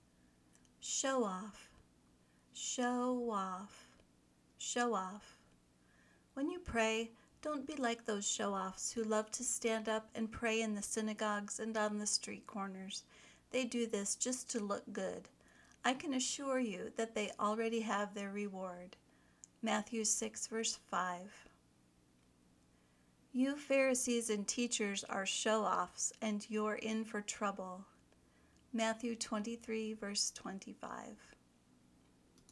ˈʃoʊ  ɑːf   (noun)